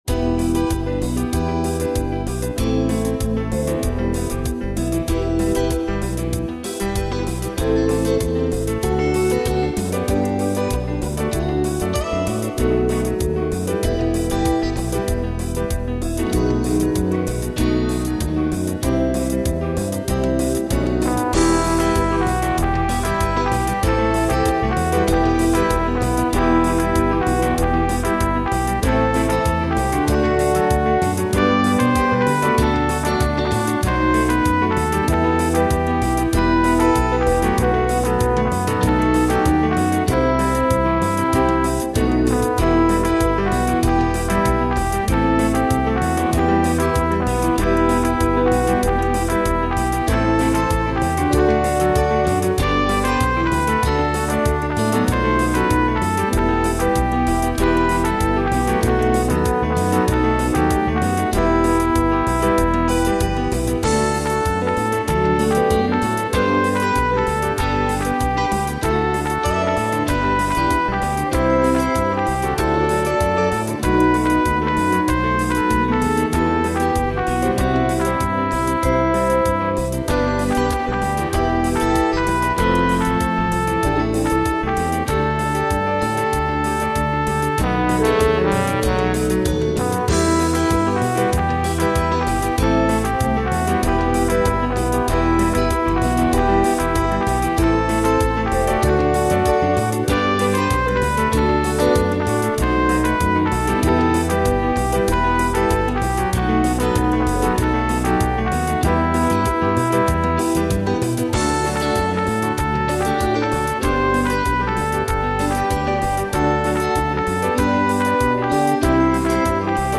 in a Latin style